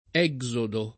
exodo [ $g@ odo ; non - 0 do ] → esodo